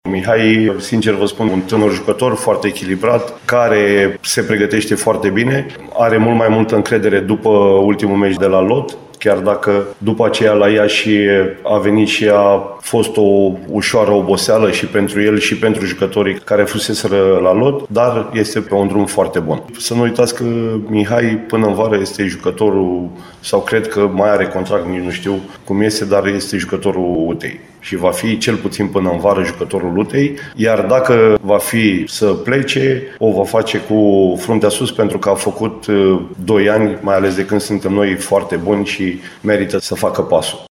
Conferința de presă a prim-divizionarei de fotbal UTA a fost susținută, astăzi